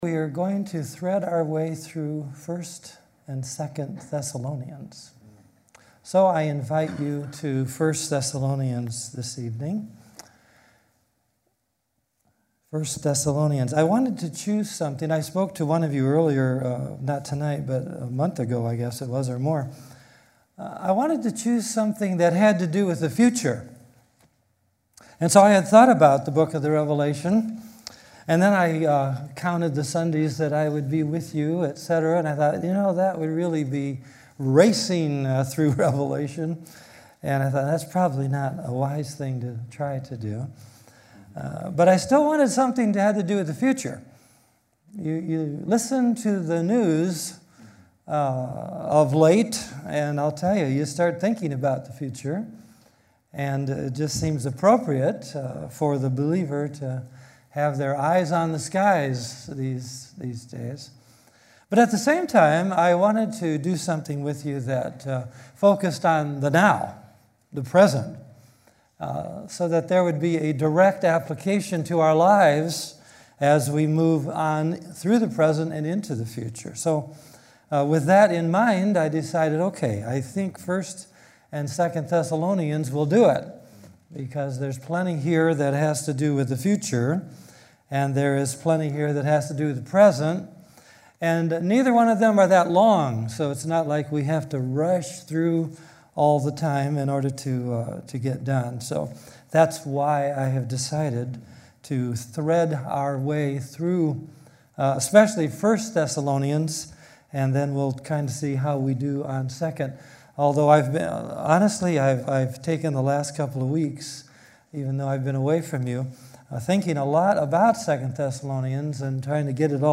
2016 Categories Sunday Evening Message Download Audio Download Notes 1 Thessalonians